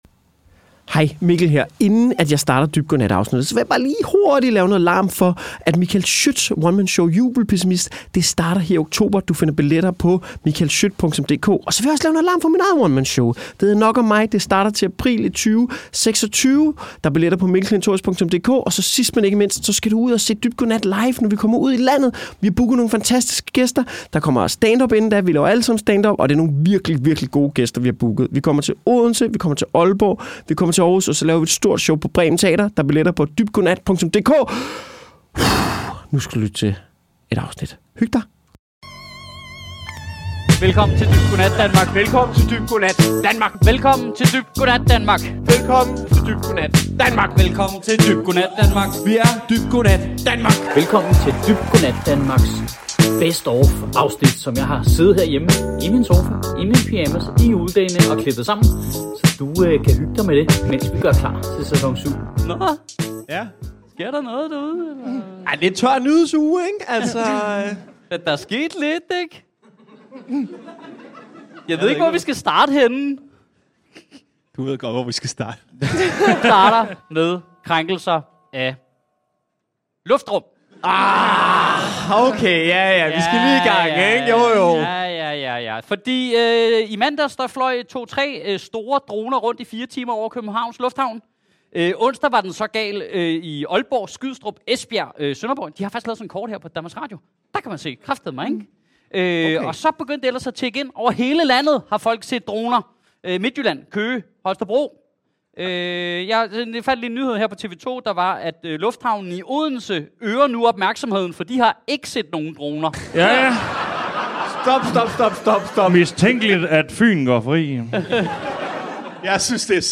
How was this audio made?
Et live-comedy-aktualitets-panel-show!